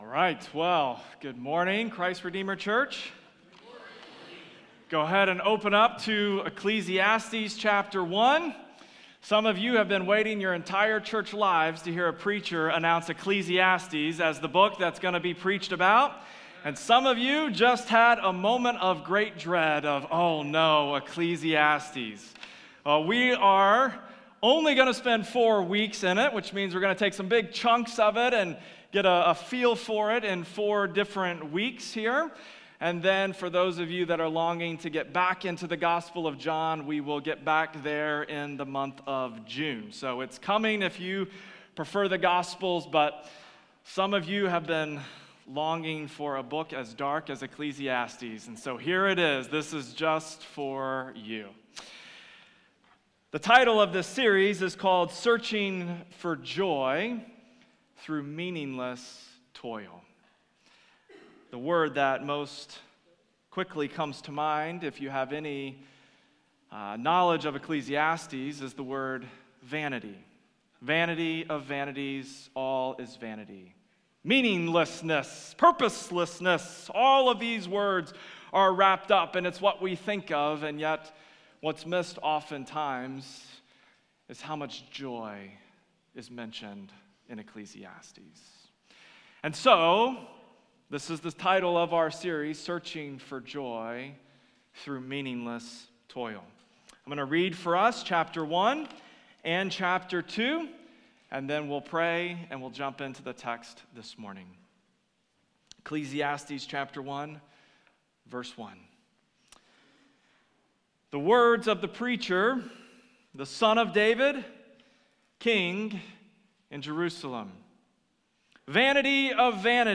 Weekly Sunday Sermons from Christ Redeemer Church in Cottage Grove, MN